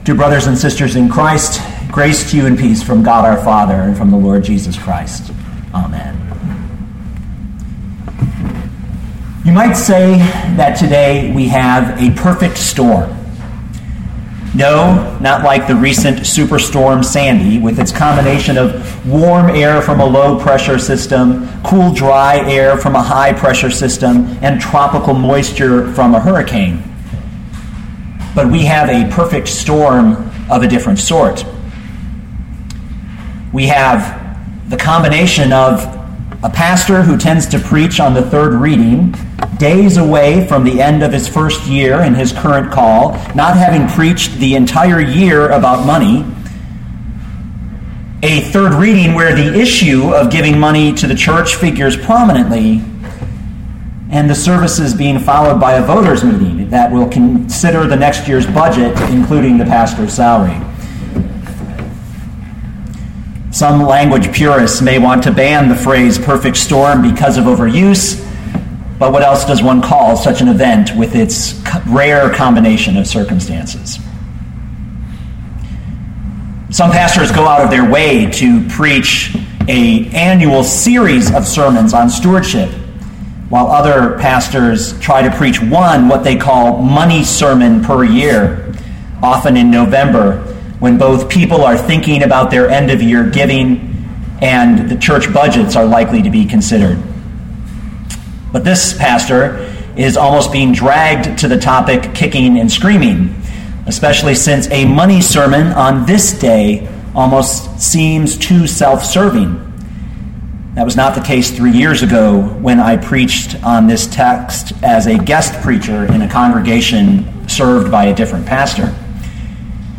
Pilgrim Lutheran Church — A Money Sermon
a-money-sermon.mp3